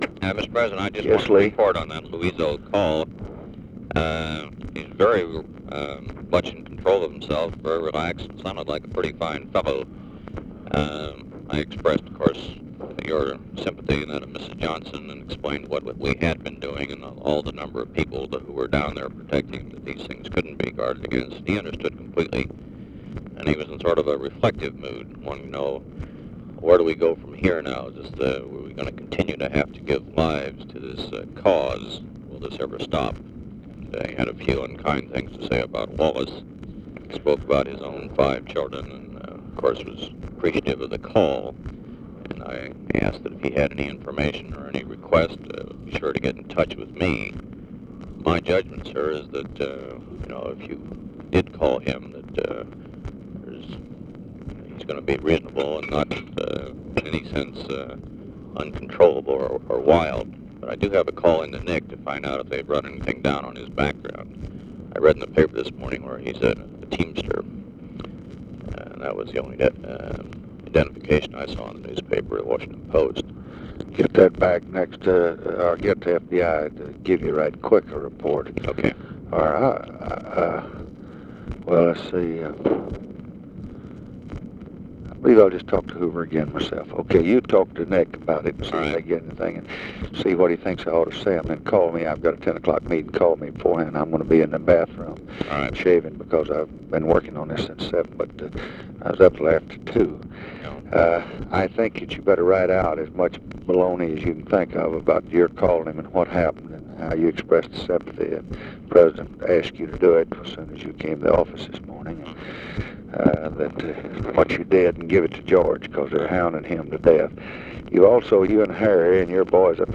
Conversation with LEE WHITE, March 26, 1965
Secret White House Tapes